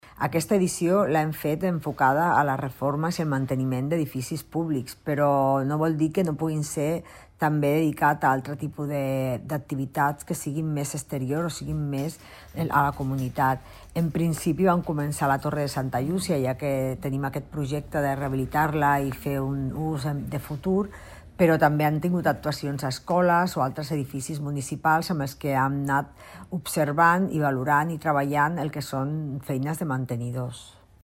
Rosa Cadenas, regidora de Promoció Econòmica de l'Ajuntament